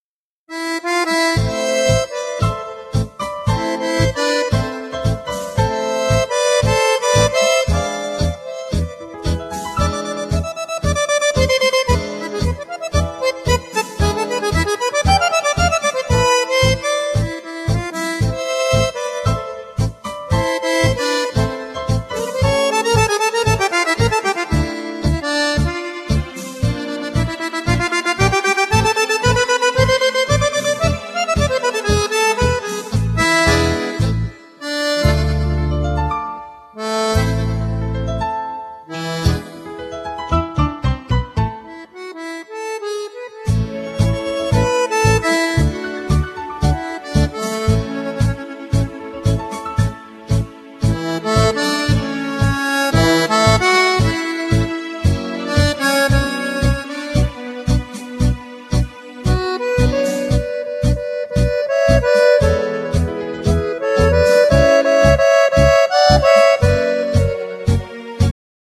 Genere : Liscio Folk